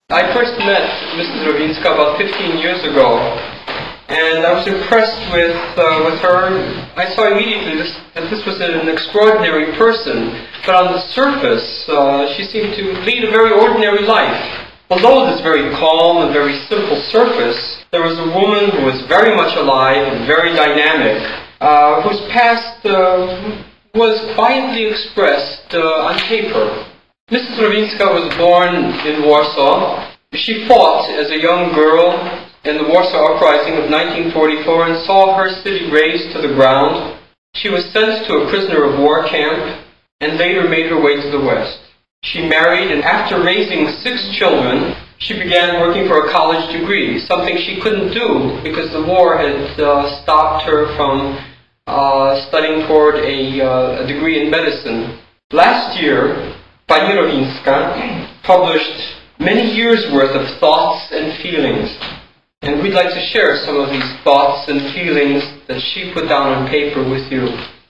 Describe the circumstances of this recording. Jones Library Amherst, MA.